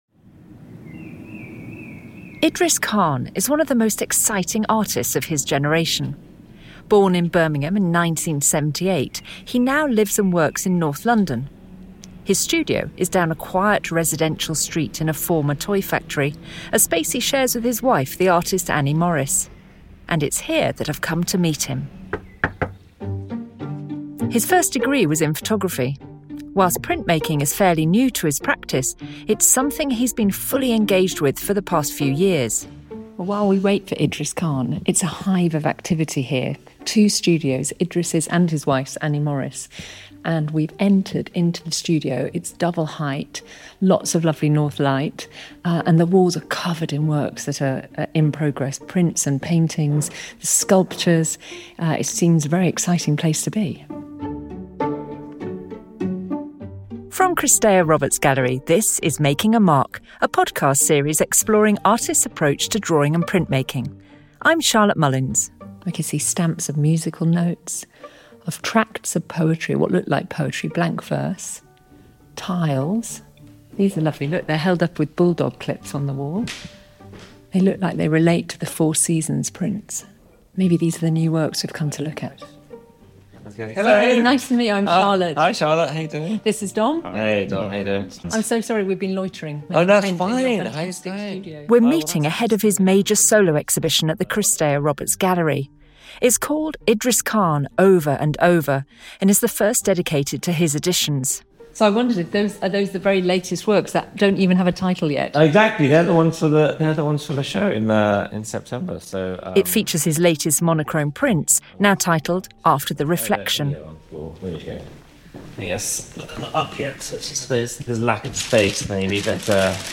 We meet Khan in his London studio where, amongst his works in progress and shelves of hundreds of letter stamps, he is finishing his newest series of prints, After the reflection , 2025. Khan discusses music, colour harmony and the abstract qualities of language that inform his work, including the recent use of advanced software to create editions layered with bands of colour, musical scores and text.